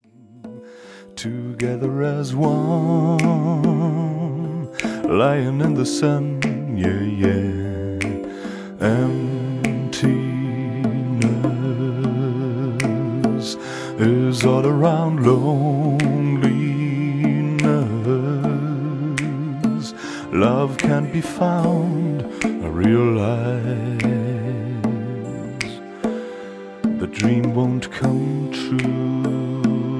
Sentimantal love song